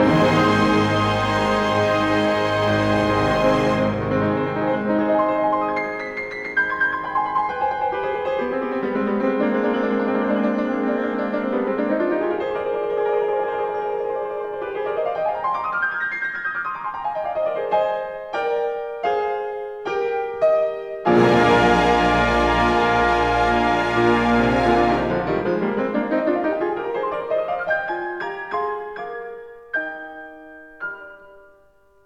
conductor
Stereo recording made on 20-23 July 1960
in No. 1 Studio, Abbey Road, London